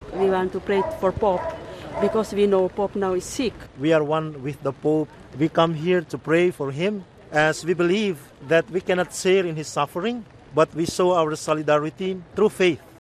This nun and priest have been explaining why they’re holding a vigil outside the hospital.